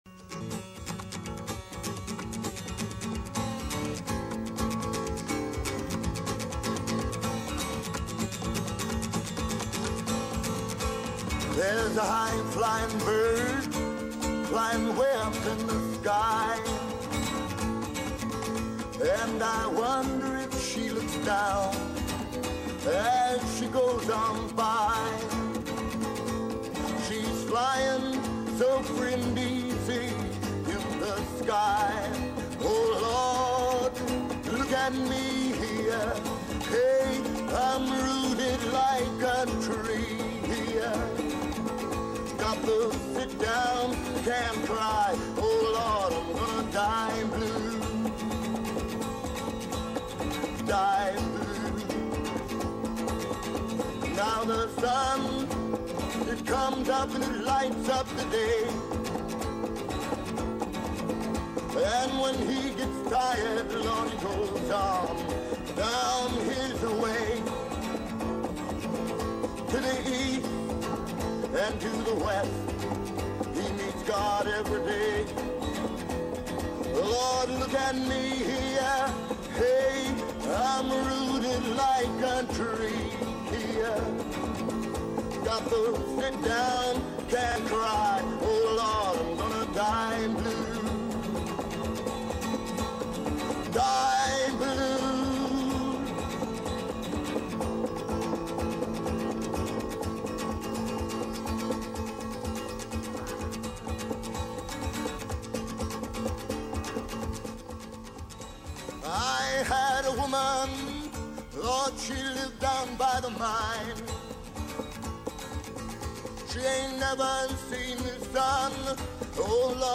Classic Rock, Psychedelic Rock, Folk Rock